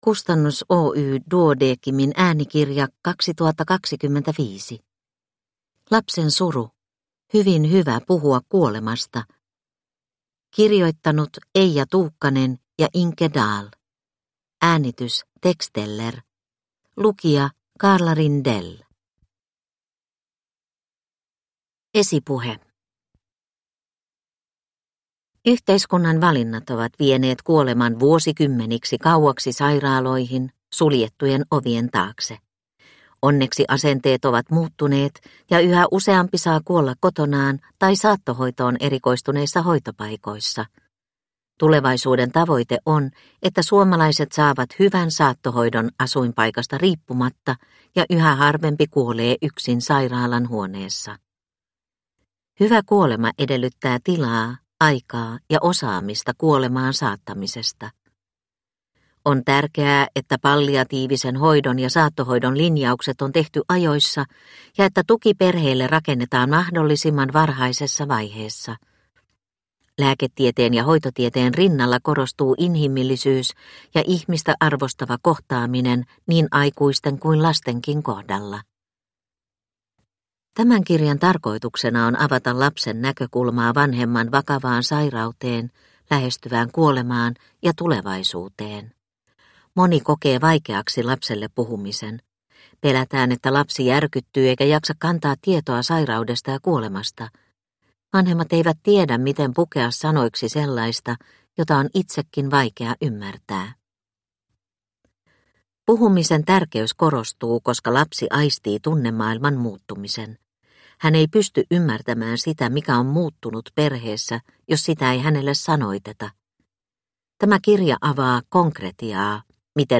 Lapsen suru – Ljudbok